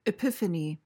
PRONUNCIATION:
(i-PIF-uh-nee)